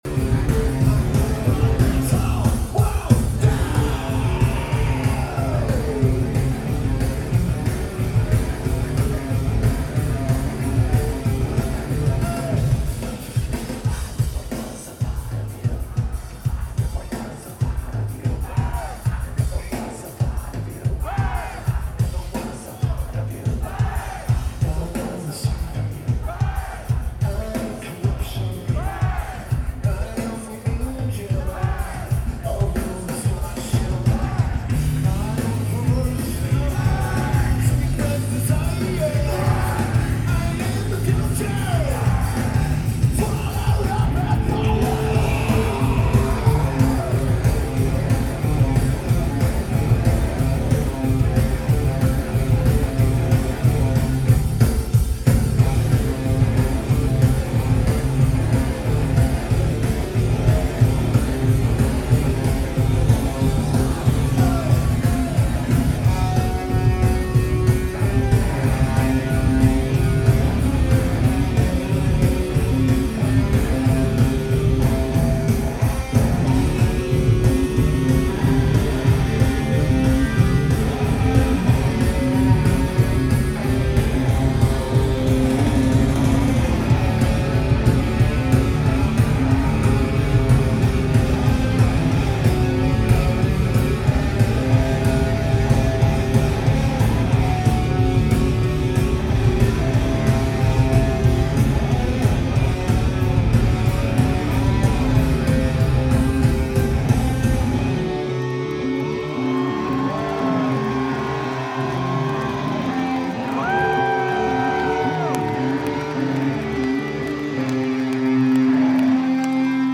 Shoreline Amphitheater
Lineage: Audio - AUD (CSBs + CSB-BB + Sony MZ-RH910)
Notes: Great recording for the last night of the tour.